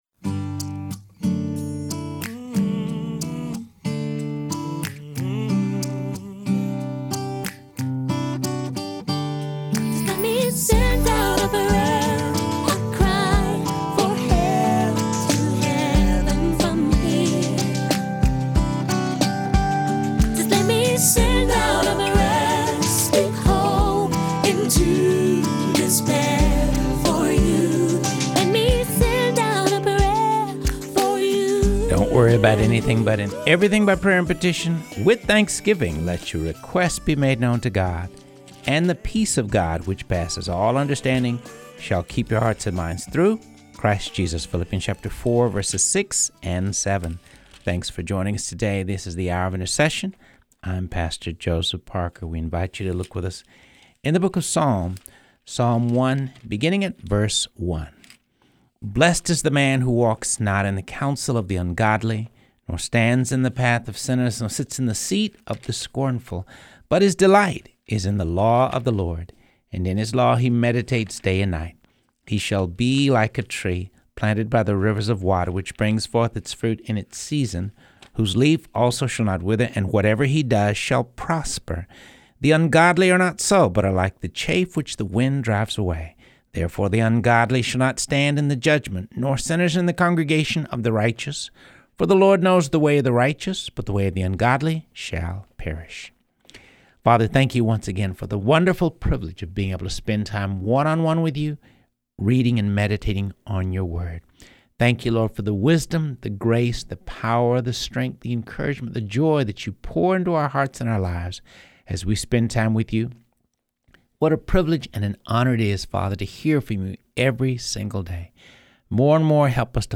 continues his project of reading through the Bible.